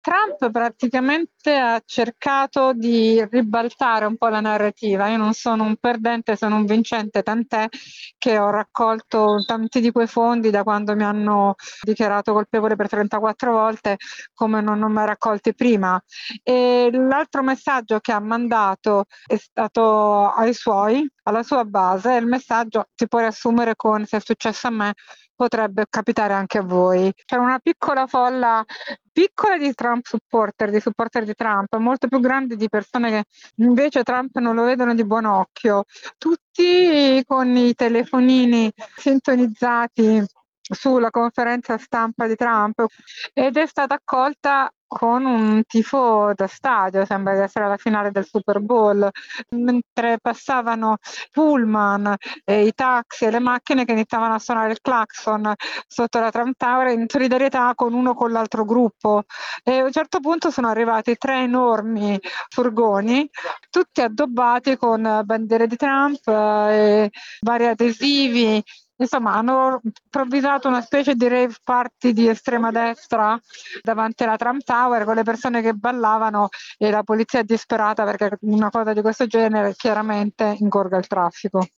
Ad ascoltarlo fuori dall’edificio c’erano persone che lo sostengono e altre – più numerose – arrivate lì per contestarlo.